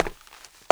HARDWOOD B.WAV